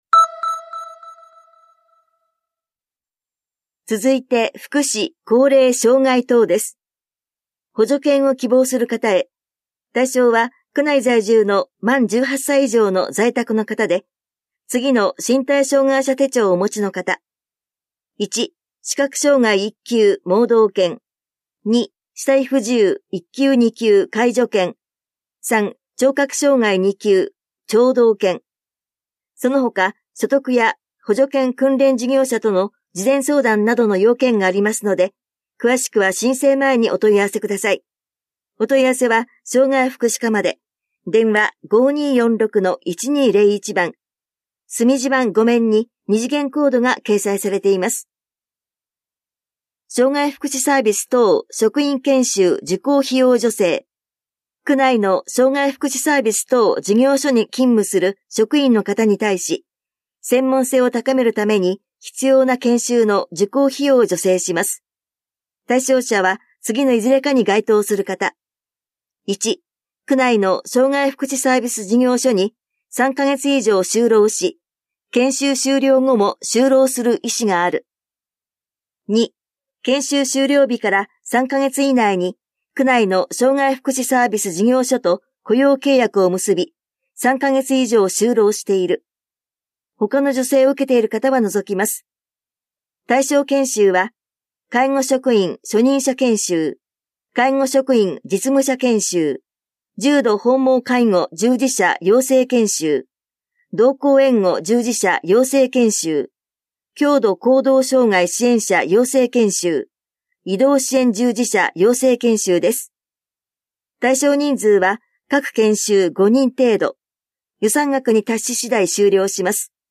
広報「たいとう」令和7年4月20日号の音声読み上げデータです。